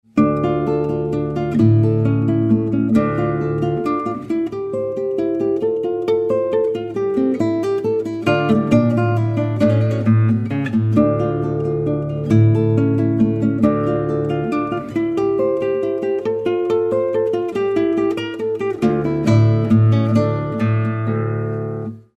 solo guitar